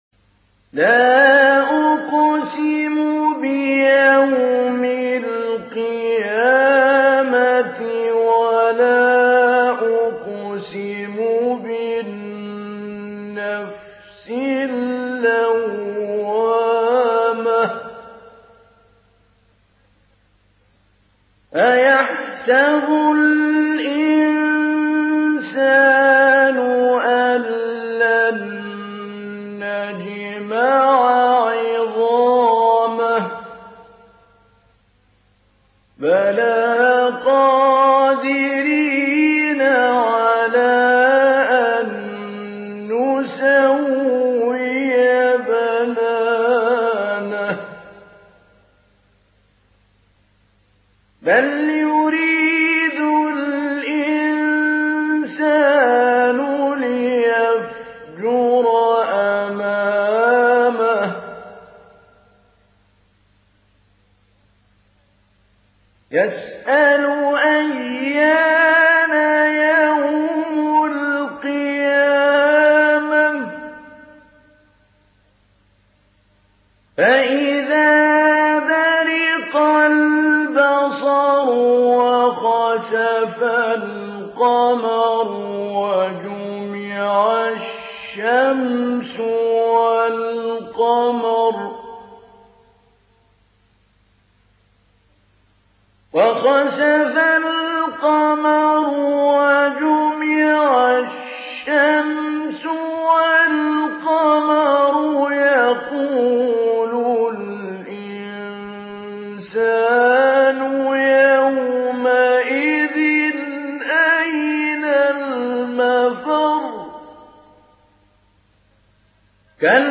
Sourate Al-Qiyamah MP3 à la voix de Mahmoud Ali Albanna Mujawwad par la narration Hafs
Une récitation touchante et belle des versets coraniques par la narration Hafs An Asim.